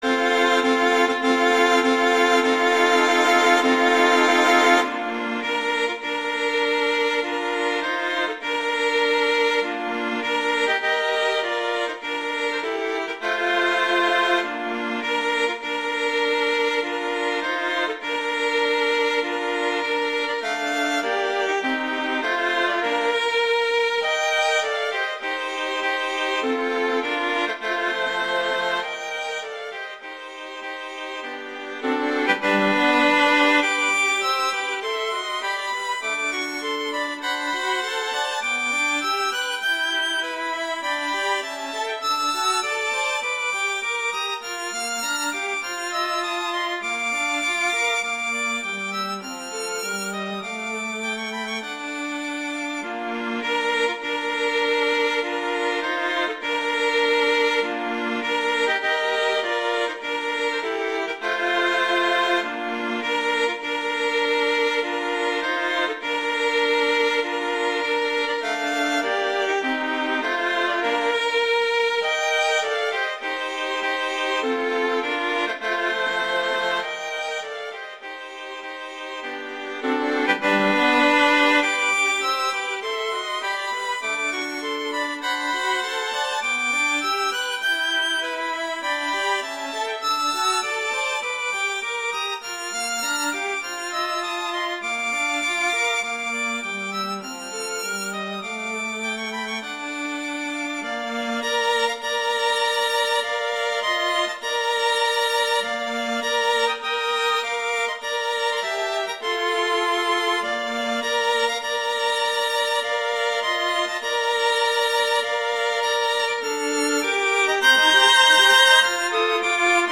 arrangements for two violins and viola